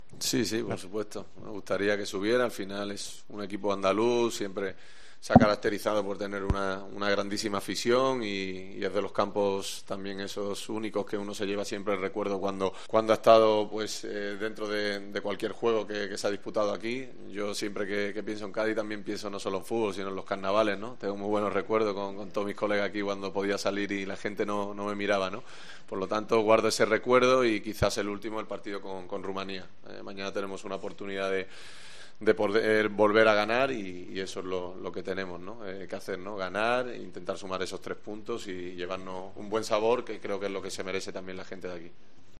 Estando en Cádiz, era menester que se le preguntara por Cádiz y por el Cádiz CF al capitán de la selección española.
Al sevillano se le preguntó por qué recuerdos le trae Carranza y ya él solito se extendió hablando del Cádiz CF, de Cádiz y de sus Carnavales.